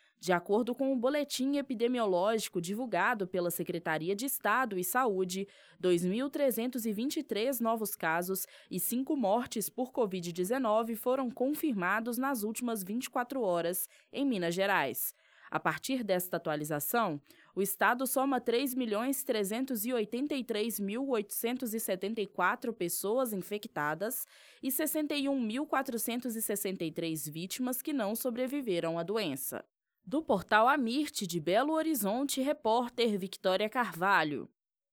Boletim: Cinco mortes são confirmadas no estado em 24 horas